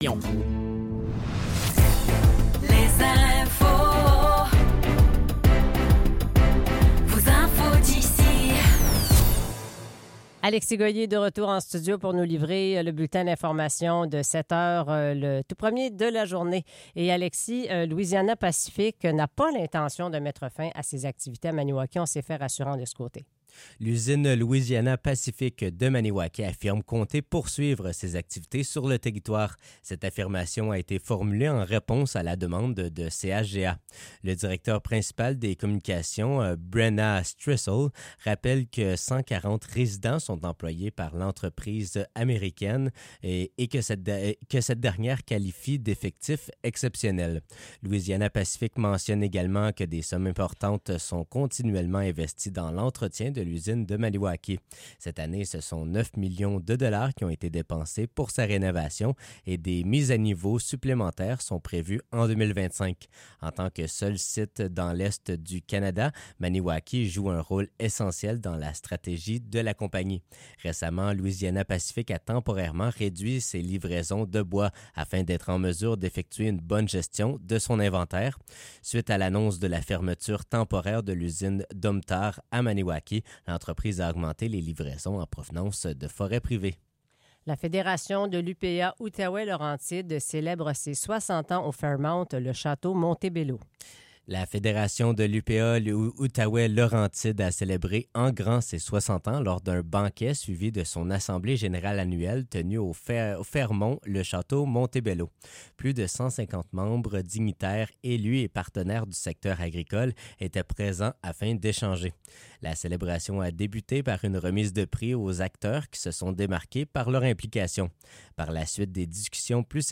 Nouvelles locales - 8 novembre 2024 - 7 h